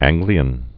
(ăngglē-ən)